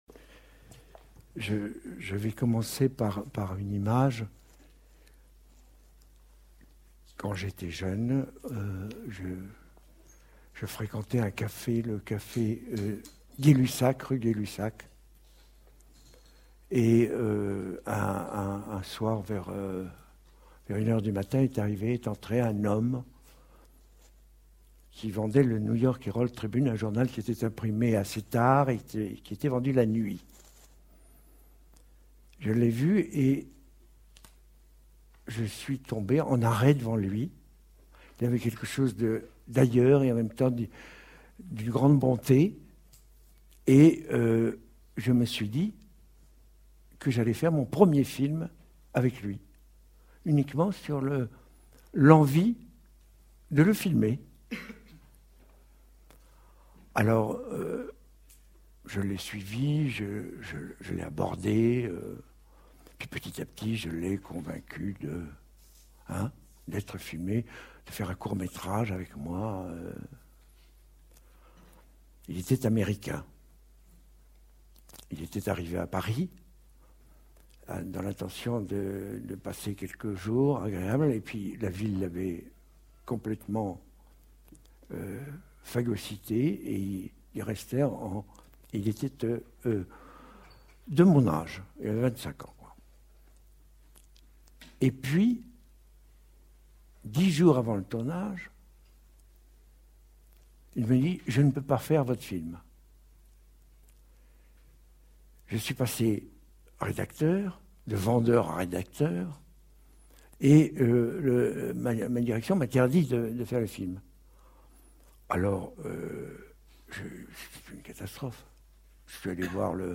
Rencontre avec Alain Cavalier | Canal U
Dans le cadre du cycle organisé autour de son œuvre, Alain Cavalier a accepté de venir à l'EHESS nous parler de son expérience cinématographique.